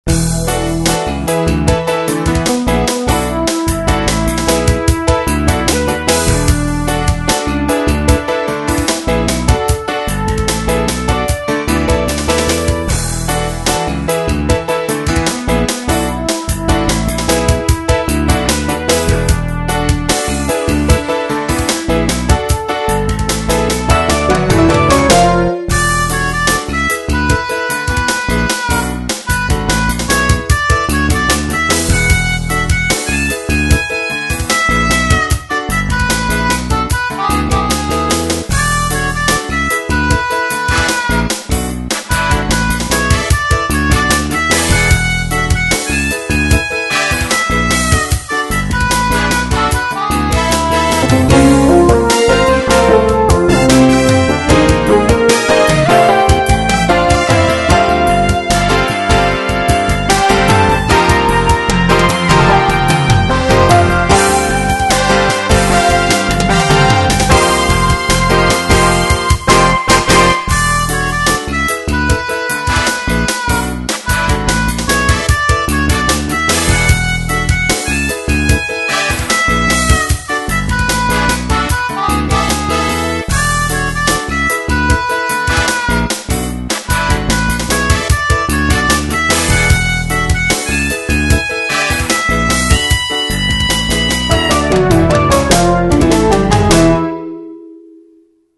Major 明るめ ちょっと主張あり